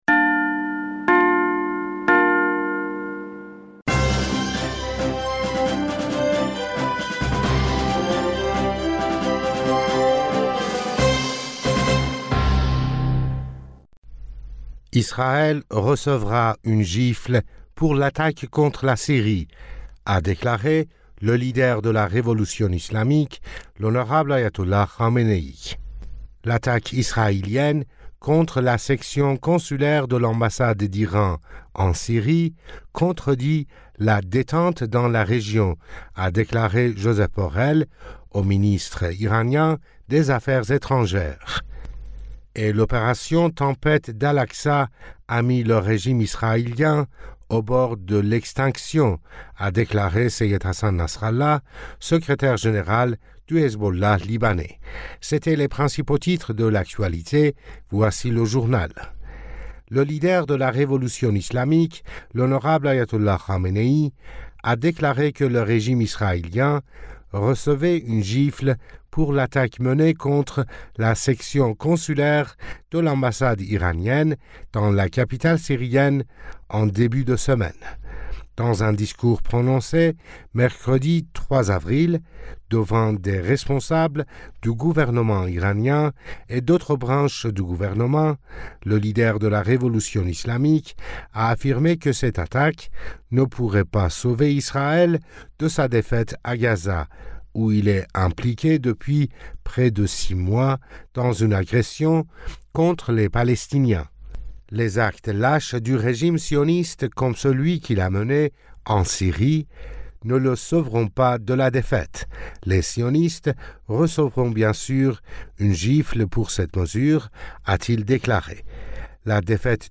Bulletin d'information du 04 Avril